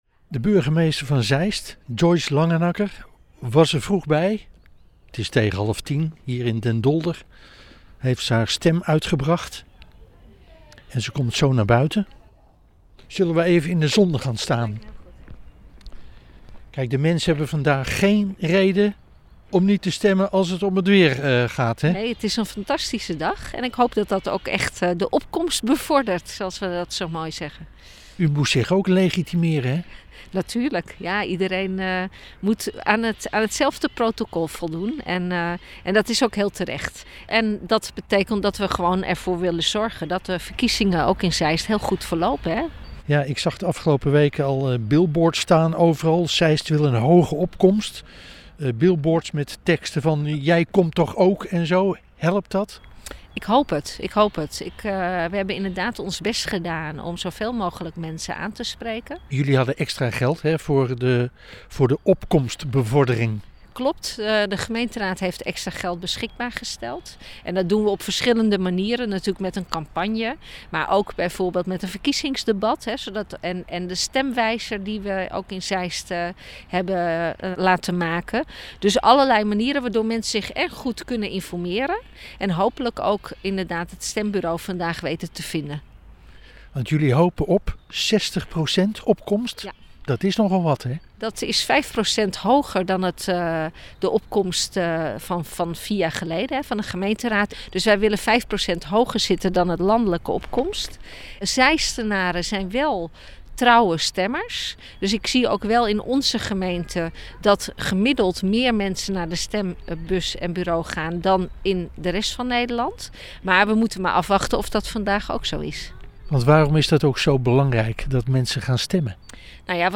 Een zonnige ontmoeting met burgemeester Joyce Langenacker bij het krieken van de dag…